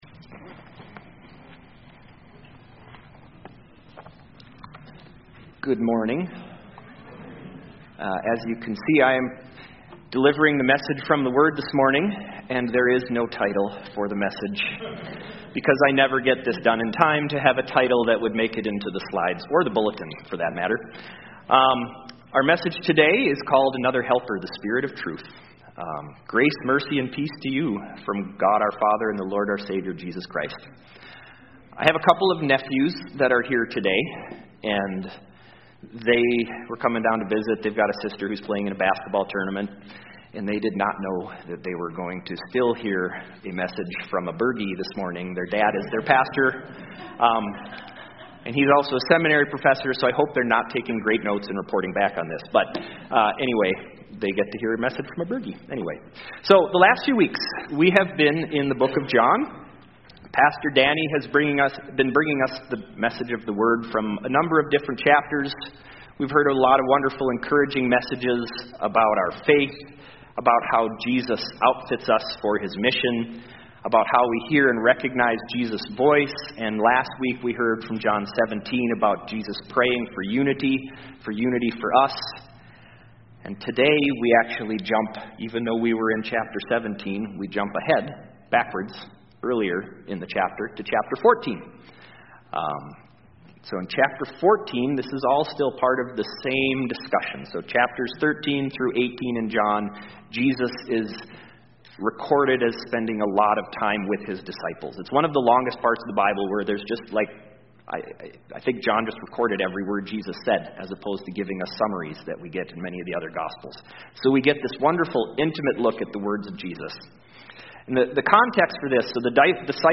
CoJ Sermons